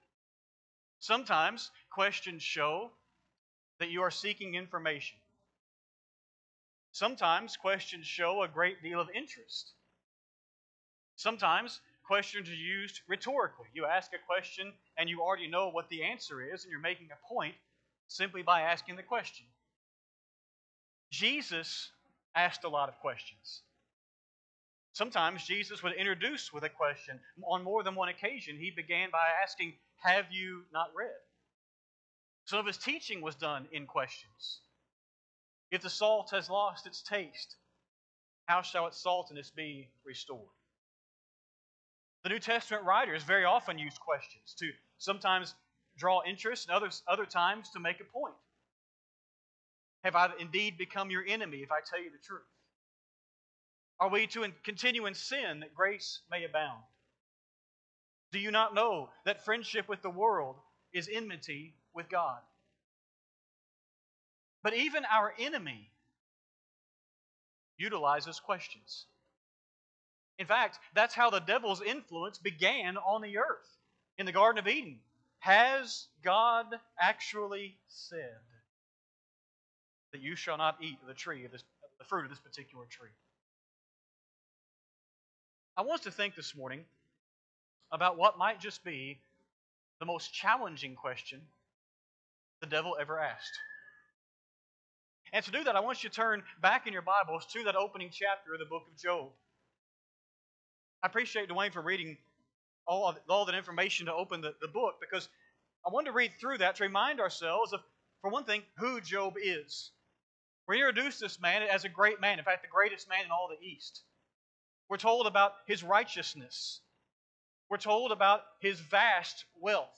7-13-25-Sunday-AM-Sermon.mp3